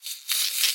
MinecraftConsoles / Minecraft.Client / Windows64Media / Sound / Minecraft / mob / silverfish / say2.ogg